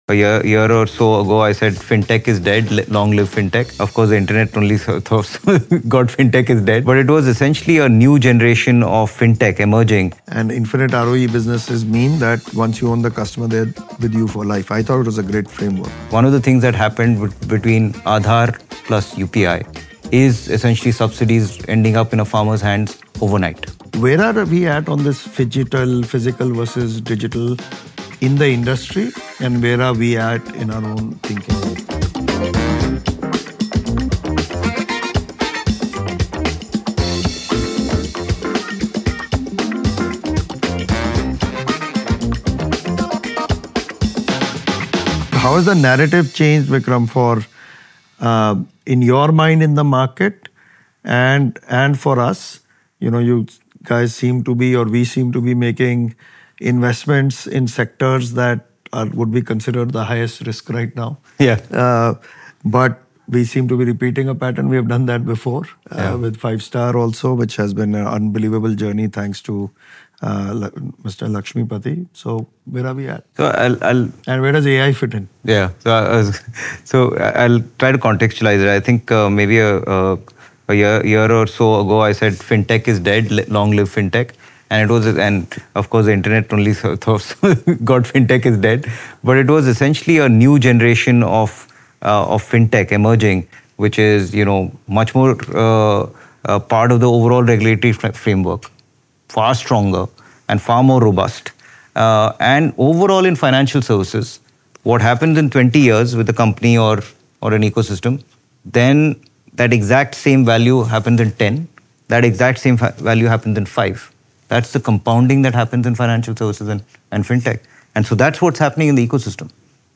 This conversation reveals the contrarian bets, the high-stakes risks, and the market forces shaping the biggest opportunities ahead.